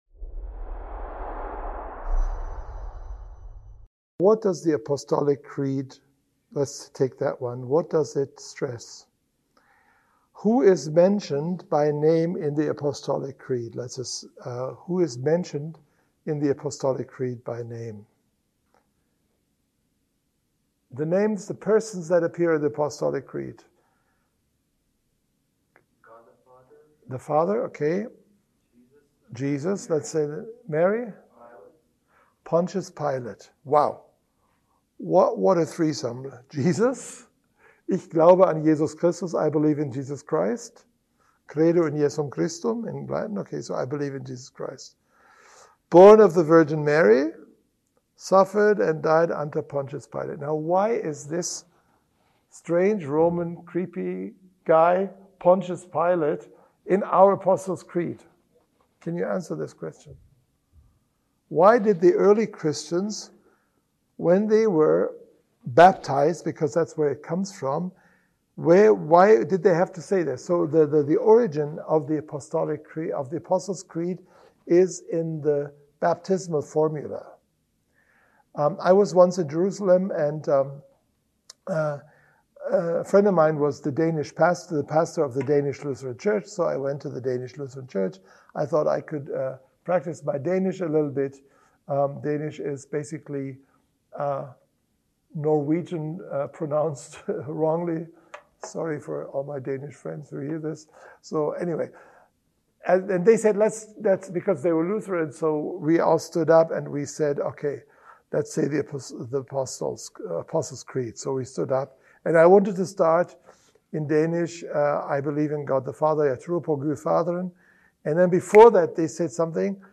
Event: ELF Pre-Forum Seminar